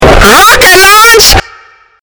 Play, download and share ROCKET LAUNSH original sound button!!!!
rocket-launsh.mp3